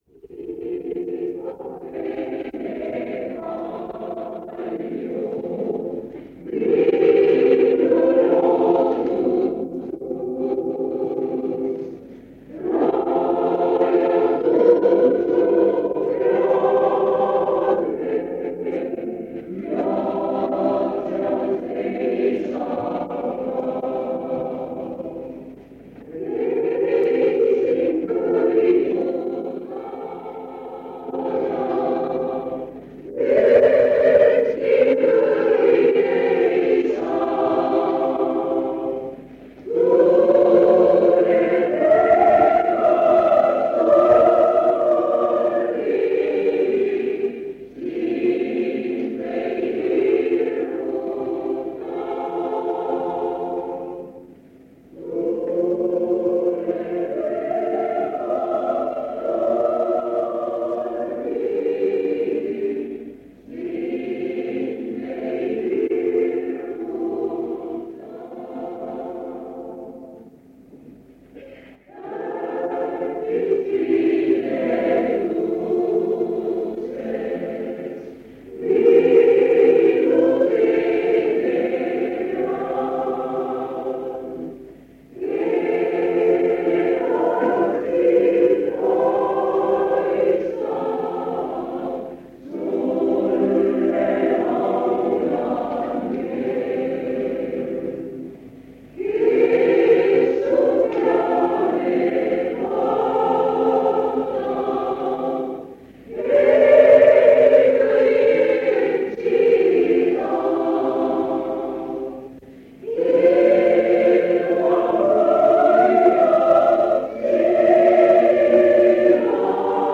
KÜSIMUSTE JA VASTUSTE ÕHTU Enne ja pärast kõnet on paar laulu.
Koosolek vanalt lintmaki lindilt 1977 aastast.